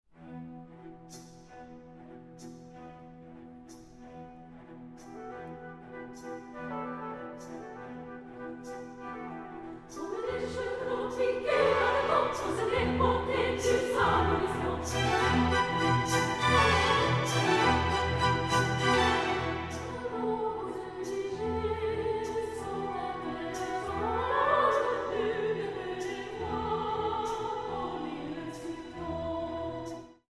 Super Audio CD
World premiere recording.